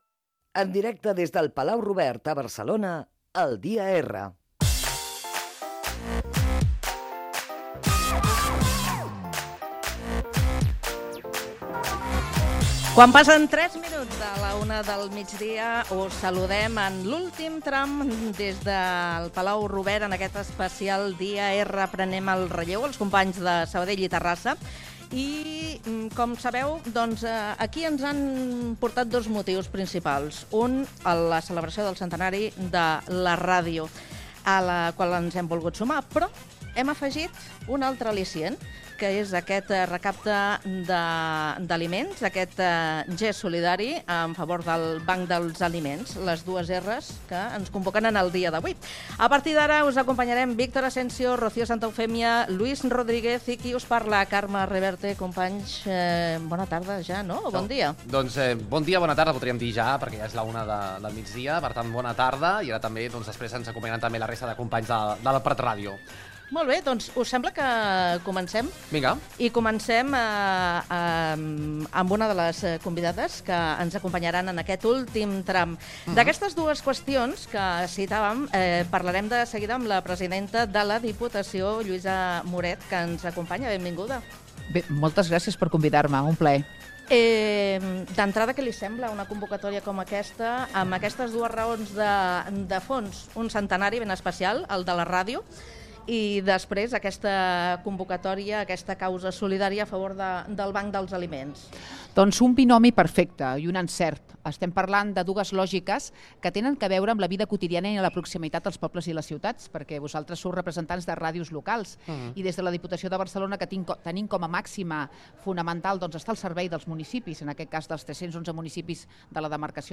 Espai fet des del Palau Robert de Barcelona.
Entrevista a la presidenta de la Diputació de Barcelona, Lluïsa Moret